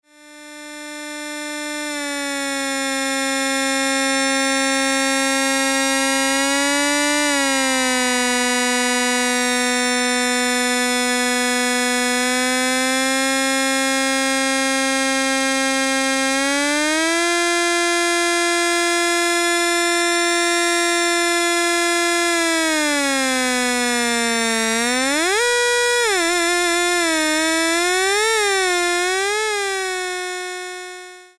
Oscillator and Modulator (Listen)
This is a box (styled to look like it could have come out of an electronic music studio in the 60s) which houses two oscillators and a ring-modulator.